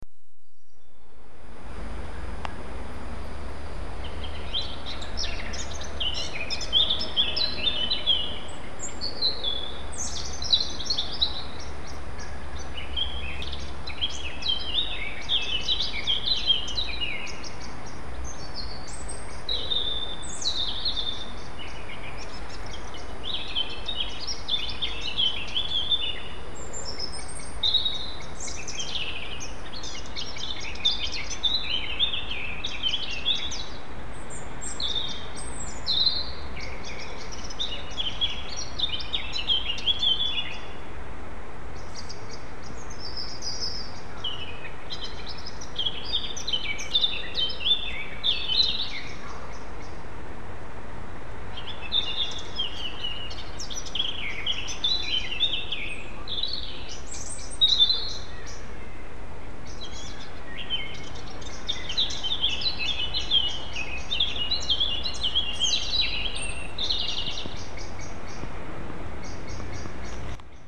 Birdsong filled the garden and on closer attention I could discern two separate songs. There was a blackcap singing high up in the large ash tree and then down by the garden shed a robin also singing, when one stopped the other started up, as if they were having a singing competition!
blackcap&robin.mp3